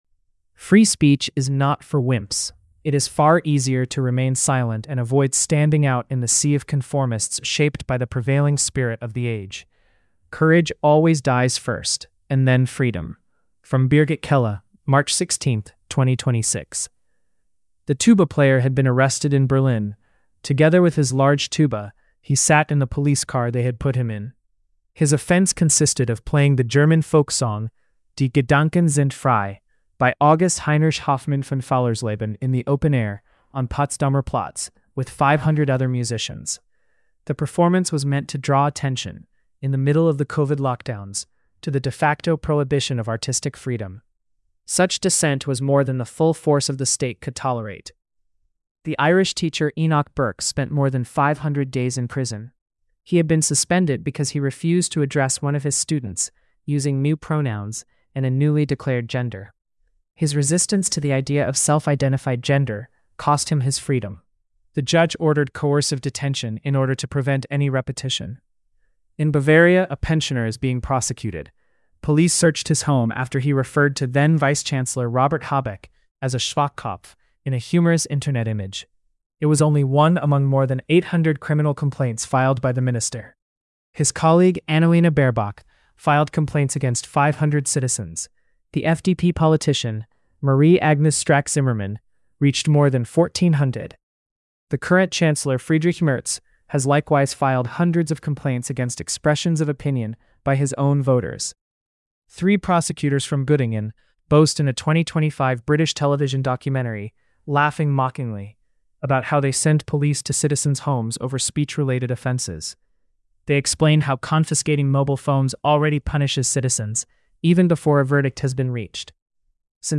pimps_female.mp3